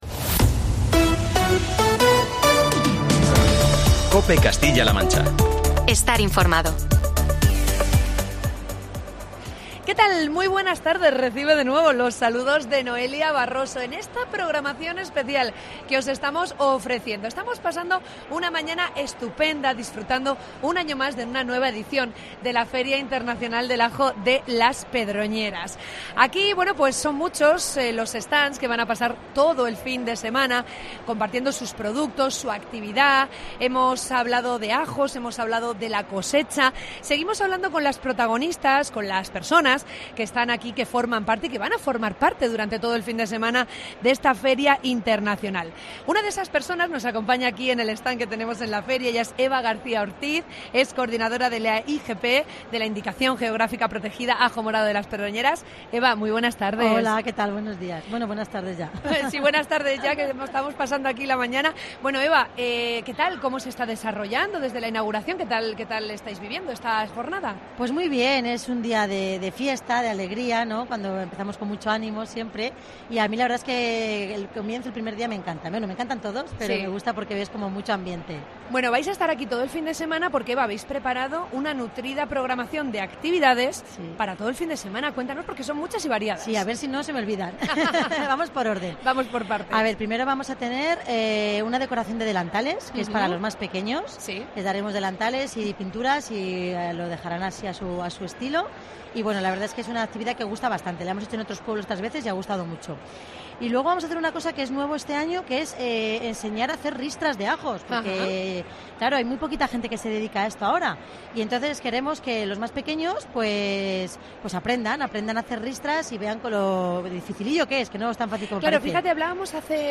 AUDIO: Programa especial desde Las Pedroñeras. Conoce los beneficios de esta maravillosa lilíacea.